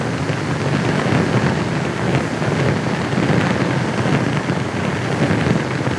FlareLoop.ogg